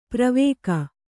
♪ pravēka